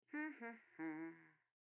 hum2.wav